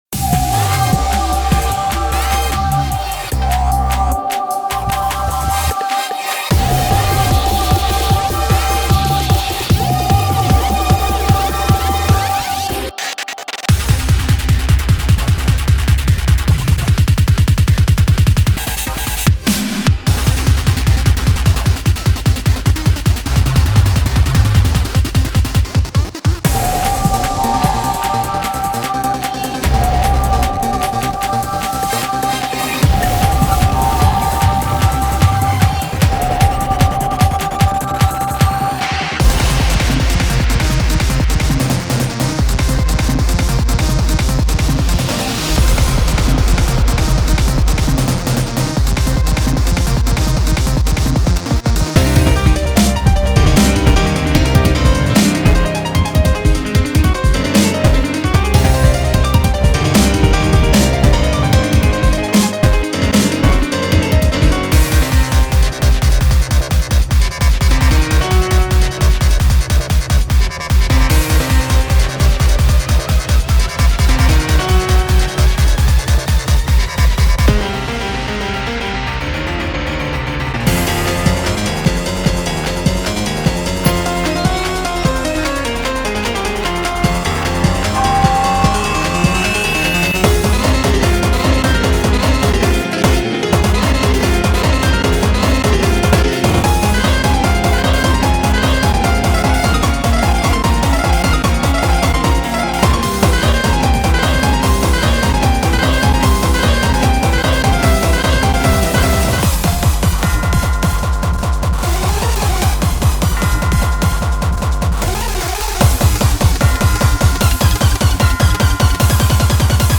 BPM301
Audio QualityPerfect (High Quality)
Commentaires[NEO HARDCORE TECHNO]